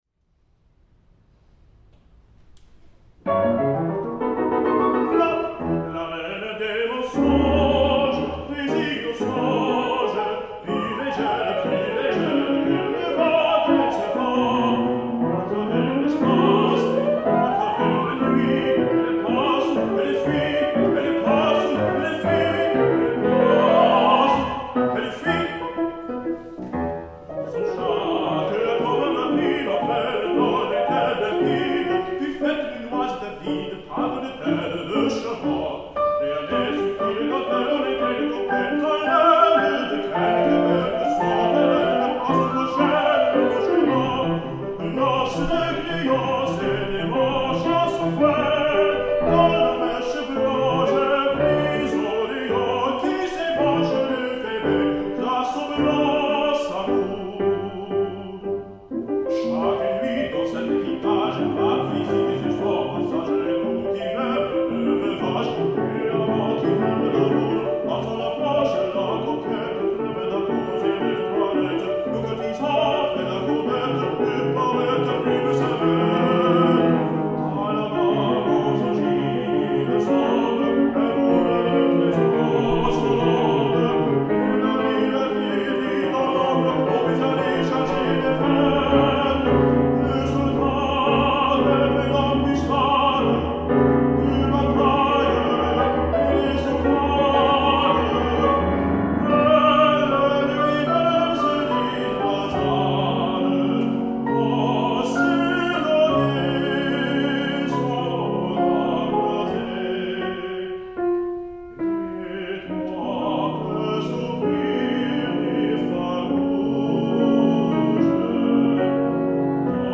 pianist
baritone